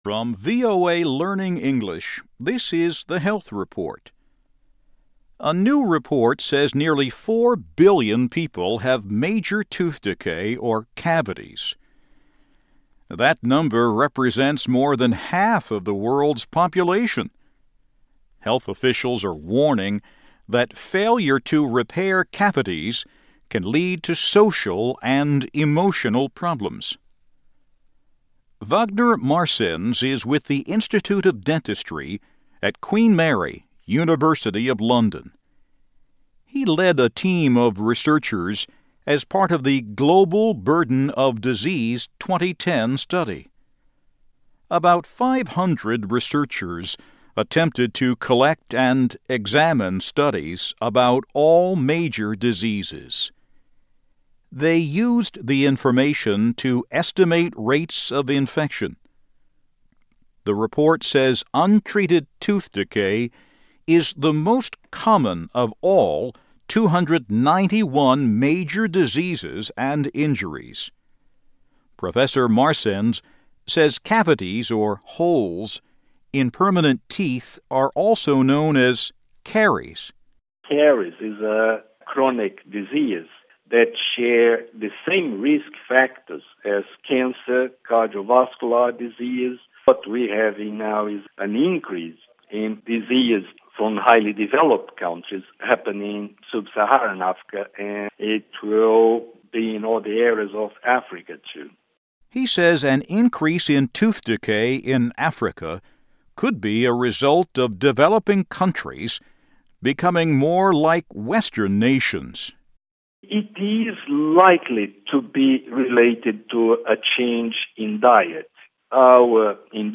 慢速英语:Poor Oral Health Leads to Social and Emotional Problems